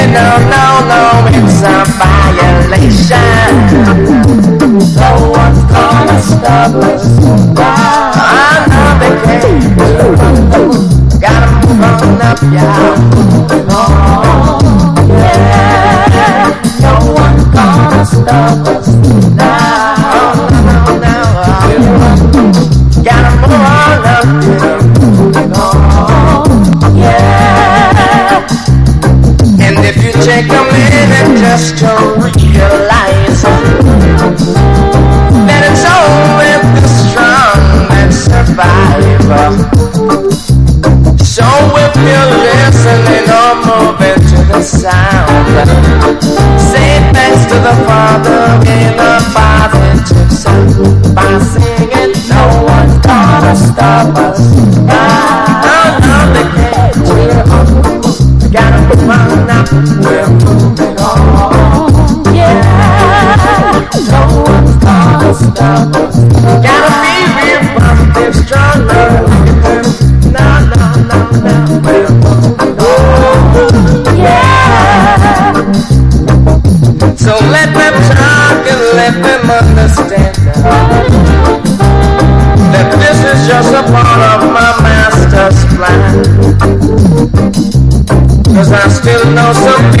• REGGAE-SKA
イントロからトロけるメロウラヴァーズ・クラシック！ゆったりしたリディムが心地よすぎます。